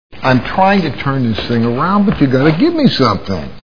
Iron Man Movie Sound Bites